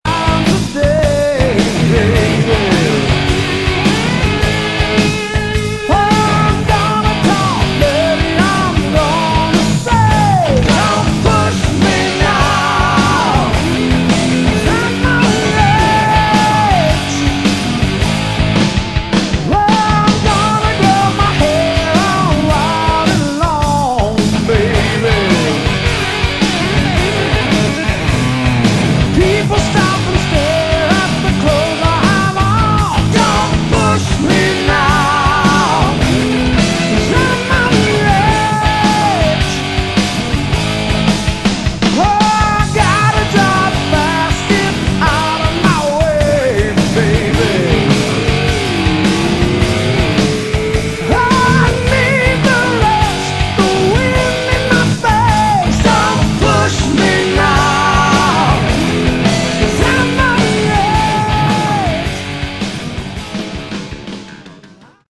Category: Melodic Rock
vocals
keyboards, guitar
bass
drums